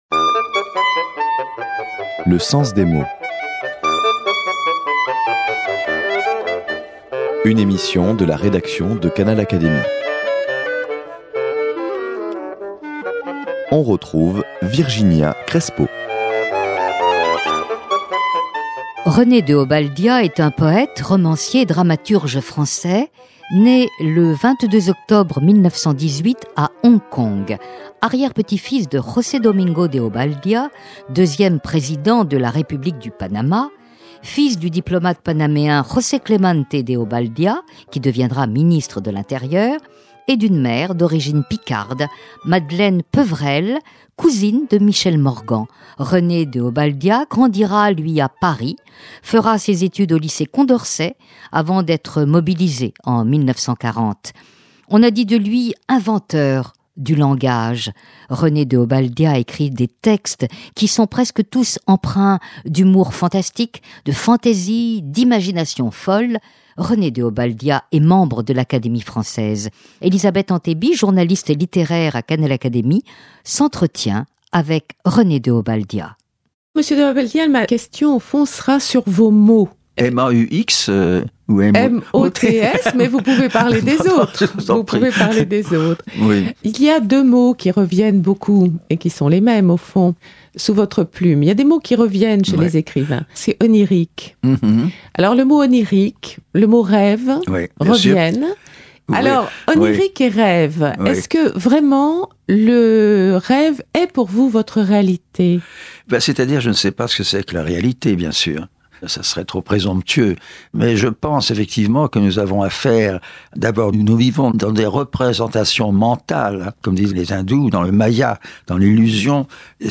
Dans l’intimité du studio de Canal Académie